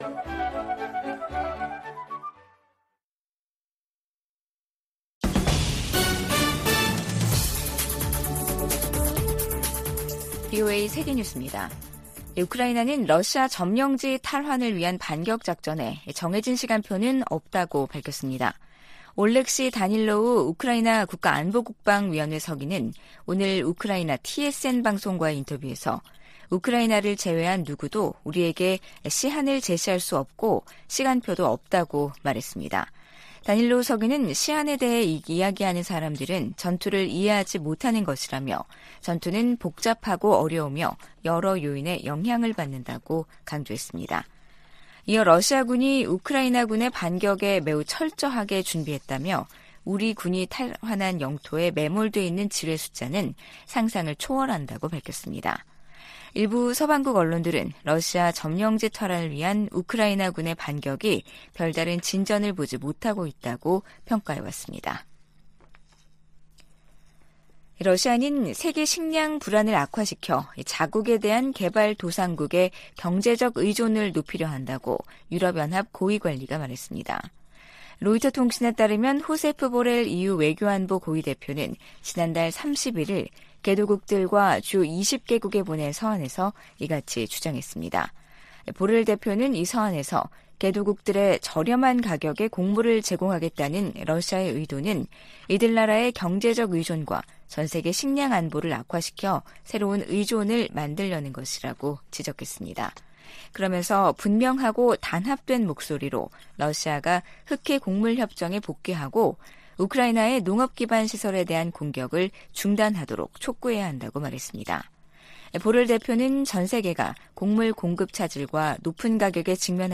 VOA 한국어 간판 뉴스 프로그램 '뉴스 투데이', 2023년 8월 3일 2부 방송입니다. 북한이 무단 월북 미군 병사 사건과 관련해 유엔군사령부에 전화를 걸어왔지만 실질적인 진전은 아니라고 국무부가 밝혔습니다. 핵확산금지조약(NPT) 당사국들이 유일하게 일방적으로 조약을 탈퇴한 북한을 비판했습니다. 김영호 한국 통일부 장관이 현 정부에서 종전선언을 추진하지 않을 것이라고 밝혔습니다.